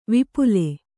♪ vipule